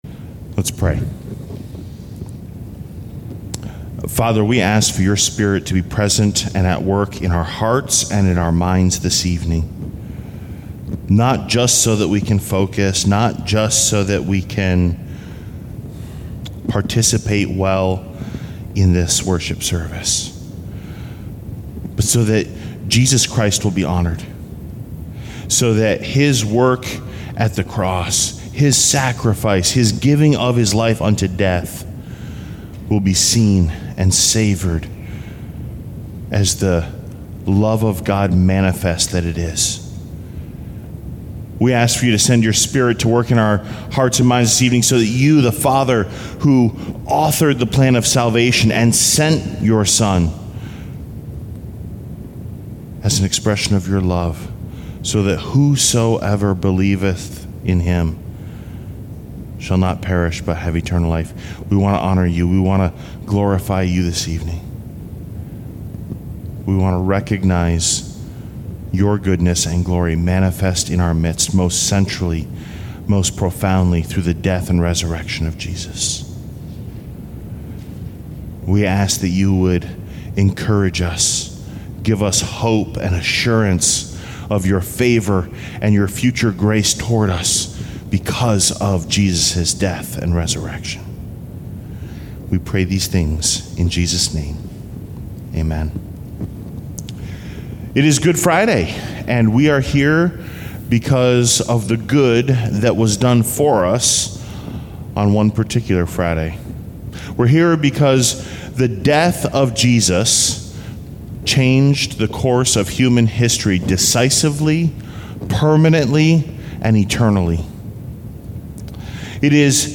Good Friday Service, 2025